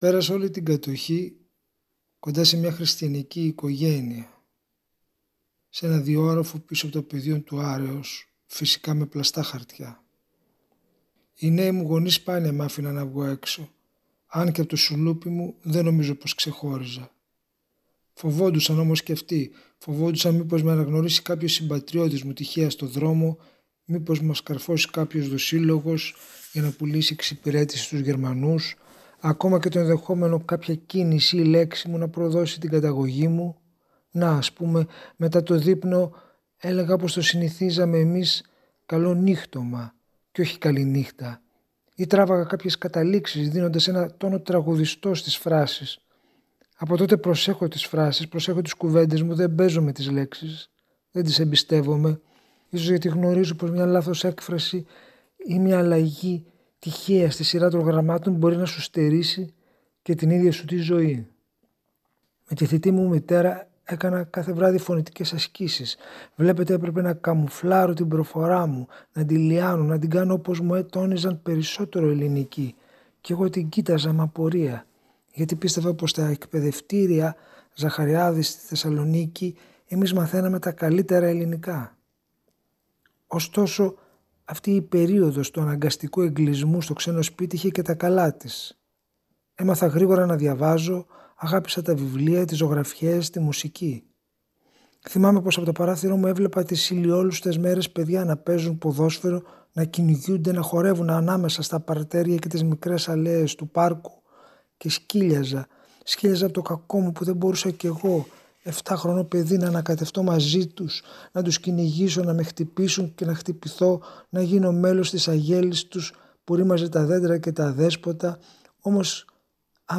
Συγγραφείς διαβάζουν στον Αθήνα 984